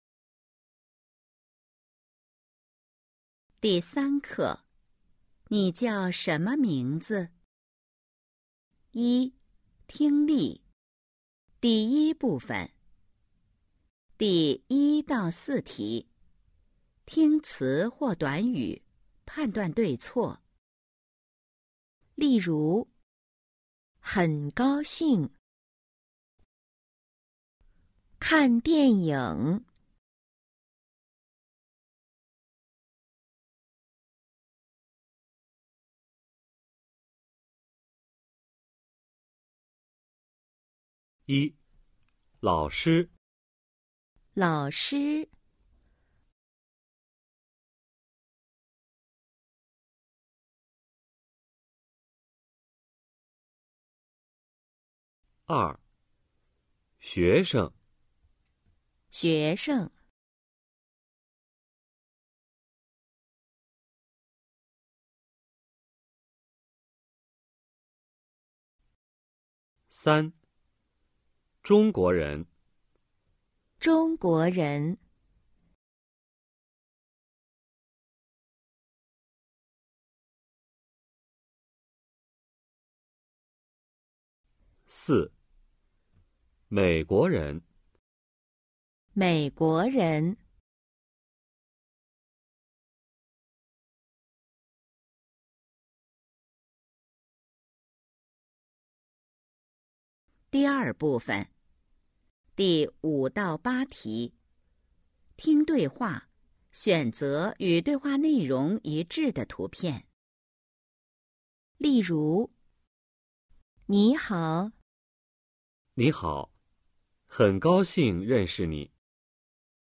一、听力 Phần nghe 🎧 03-1
Câu hỏi 1-4: Nghe các từ/cụm từ và cho biết những hình sau có mô tả đúng thông tin bạn nghe được hay không.